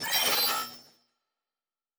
pgs/Assets/Audio/Sci-Fi Sounds/Electric/Data Calculating 5_1.wav at master
Data Calculating 5_1.wav